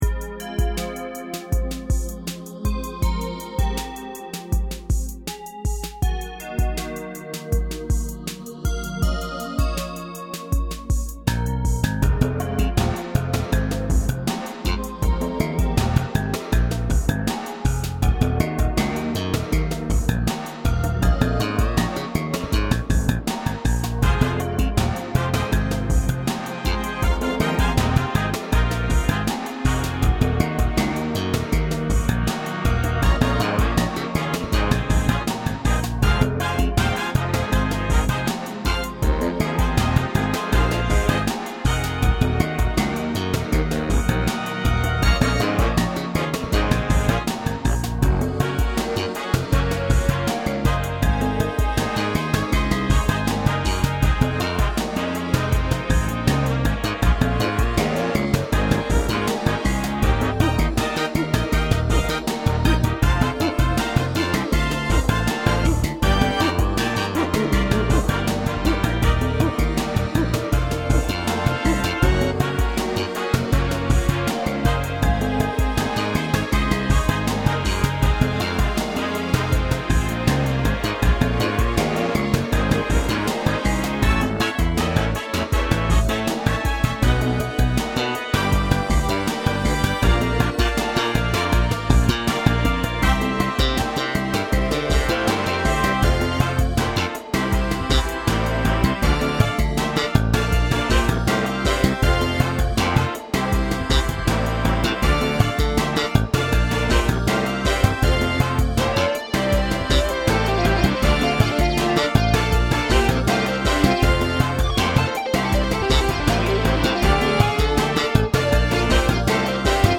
BASS MUSIC; GROOVE MUSIC